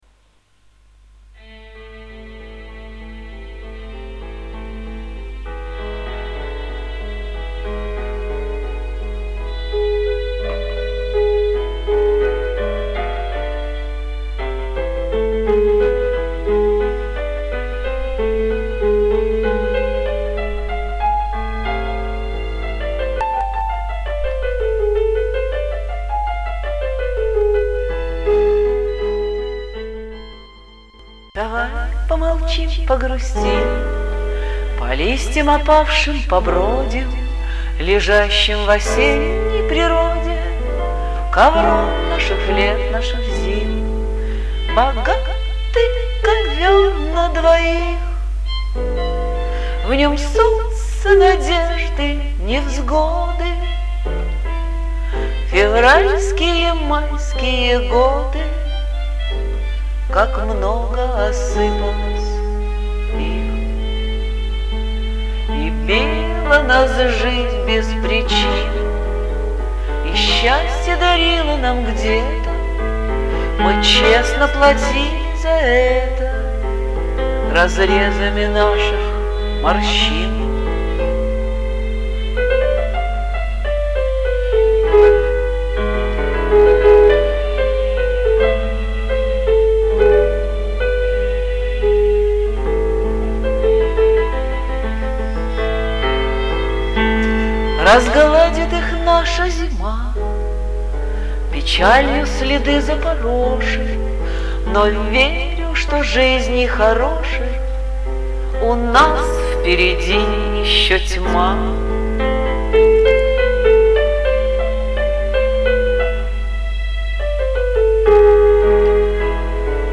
Красиво, талантливо!Только звук не такой чистый, как следовало бы сделать! 12 friends
этот вариант и нежней и мелодичней мои апплодисменты clapping